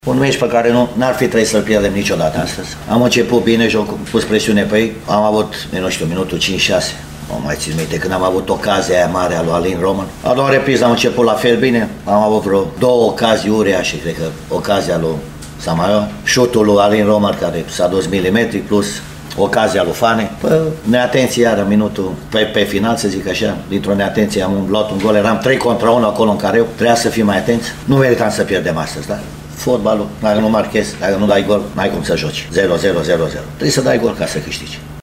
Tehnicianul ieșenilor, Vasile Miriuță, consideră că echipa sa nu merita să piardă: